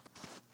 Sand Foot Step 4.wav